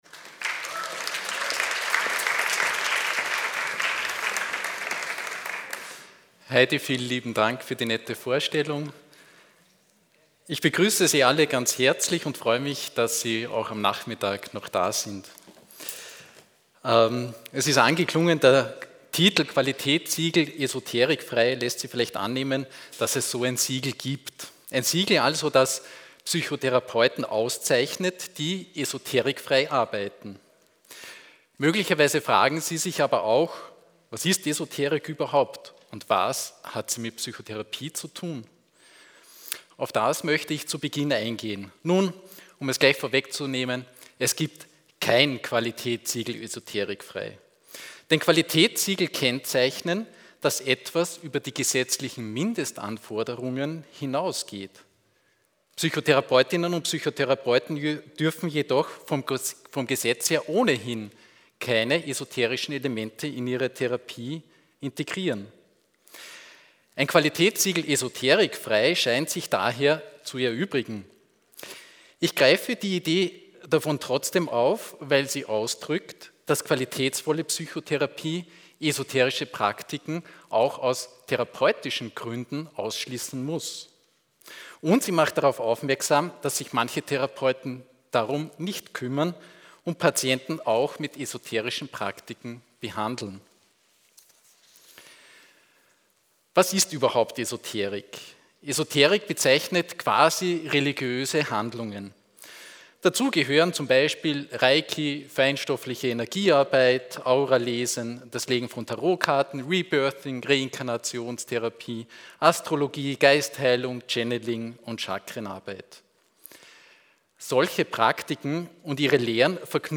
Juli 2025, S. 18 pdf Ungekürzte Version des Artikels mit Nachweisen pdf Vortrag Qualit�tssiegel Esoterikfrei. Woran Sie esoterikfreie Psychotherapie erkennen und warum das wichtig ist anima � Tag f�r psychische Gesundheit , Dornbirn, �sterreich, 16.